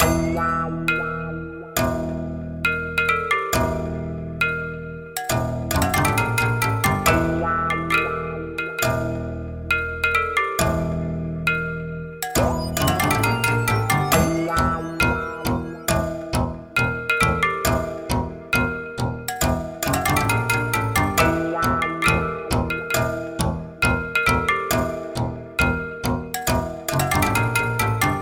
描述：肮脏的西海岸类型的狗屎 非洲打击乐
Tag: 136 bpm Trap Loops Percussion Loops 4.75 MB wav Key : Unknown